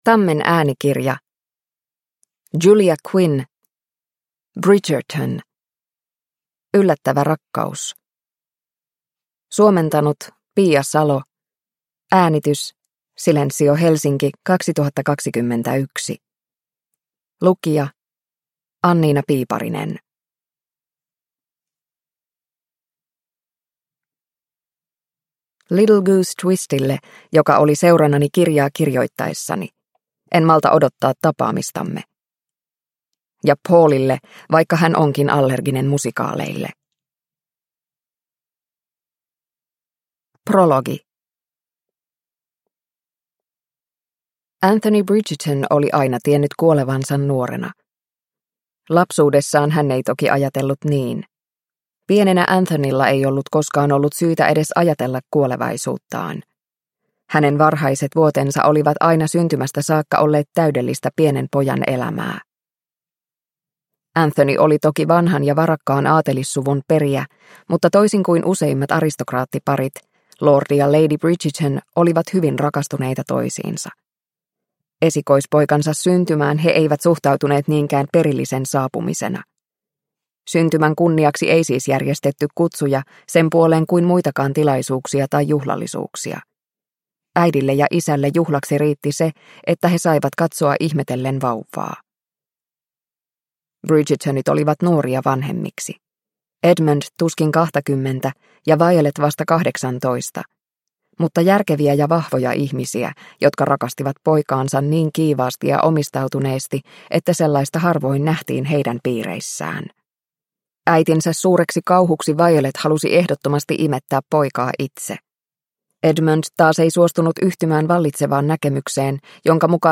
Bridgerton: Yllättävä rakkaus – Ljudbok – Laddas ner